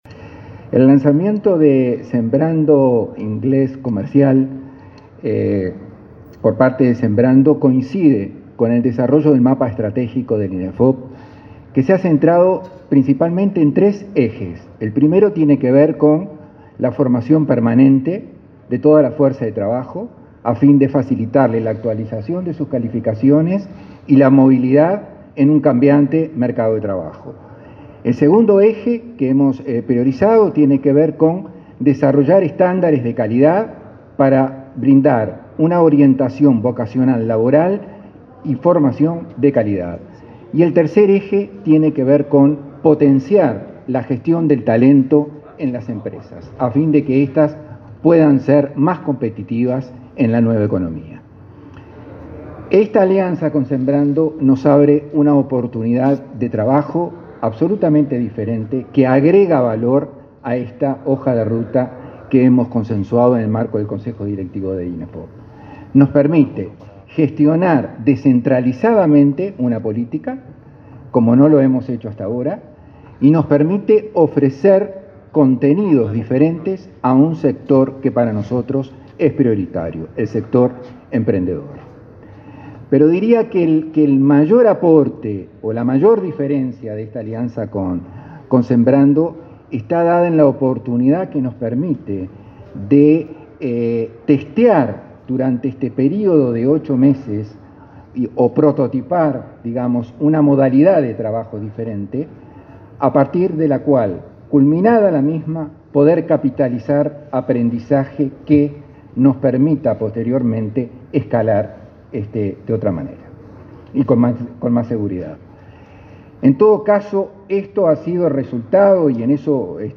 Declaraciones de Guillermo Dutra sobre el lanzamiento de Sembrando Inglés Comercial